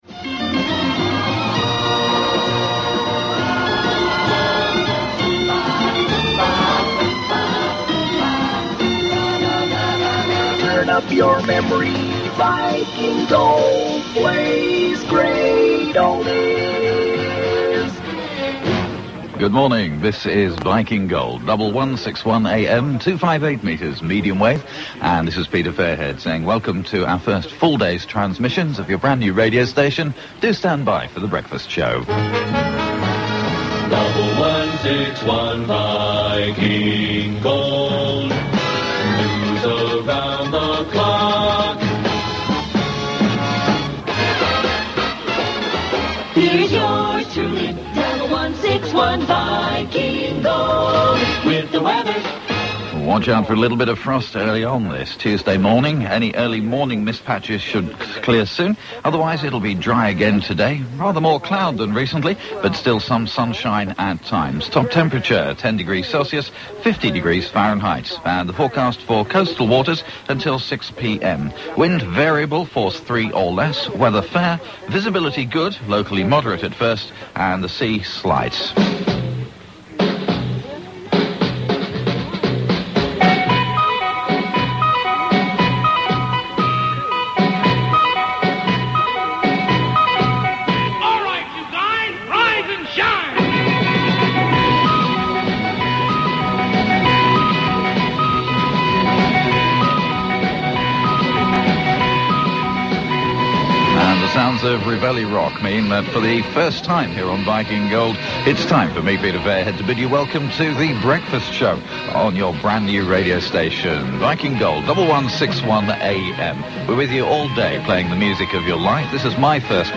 Though not the first station to completely split on a permanent basis Viking Gold was an early example of the now familiar Gold service complete with resings of those classic 60s PAMS jingles.